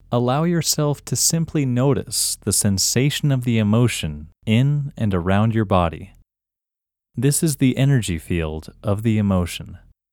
OUT – English Male 3